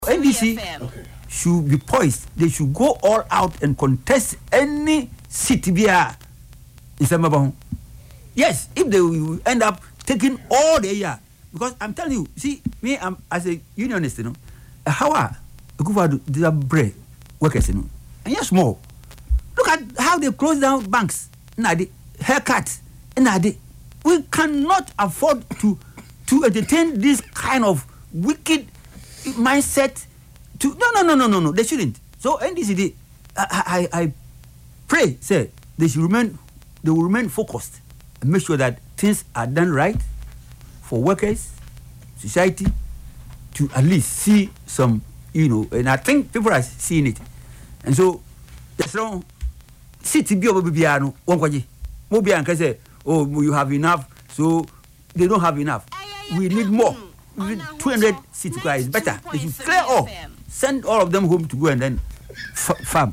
Speaking on Ahotor FM’s Yepe Ahunu show on Saturday